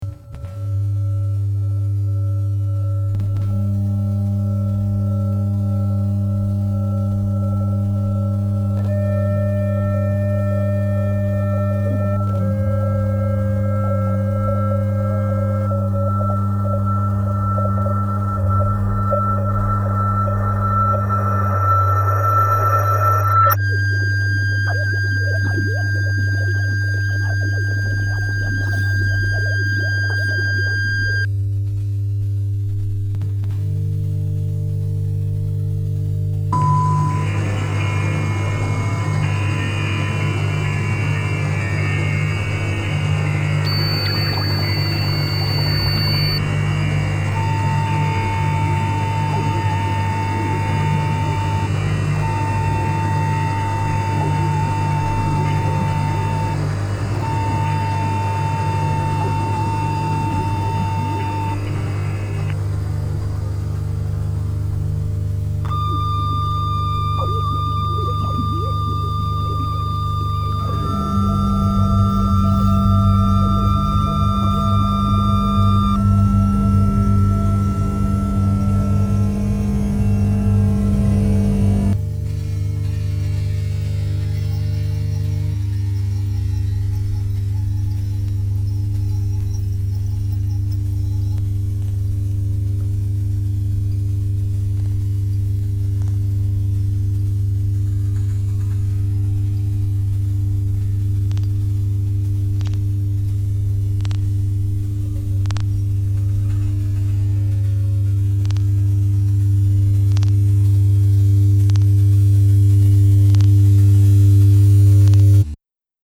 Style Style Ambient
Mood Mood Intense, Scary
Featured Featured Synth